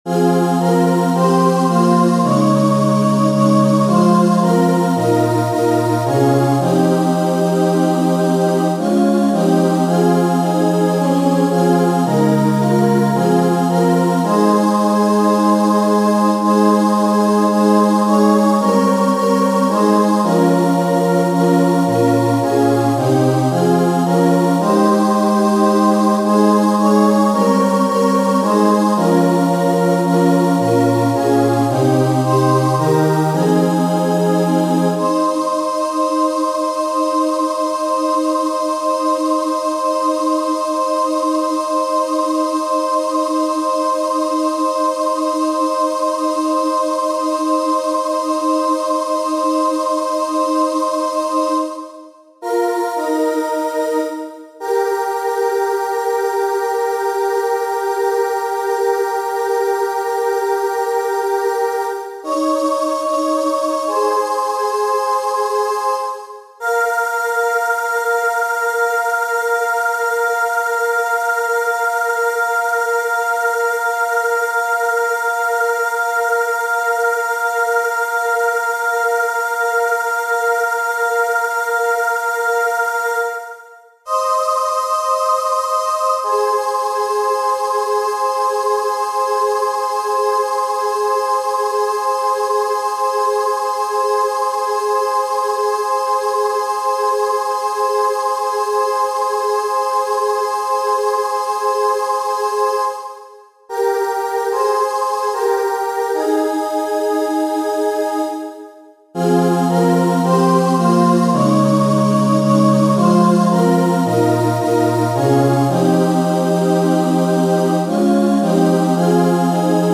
Chant de méditation, à usage de prière avec le Psaume 91
• Catégorie : Chants de Méditation